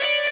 guitar_019.ogg